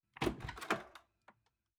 开门.wav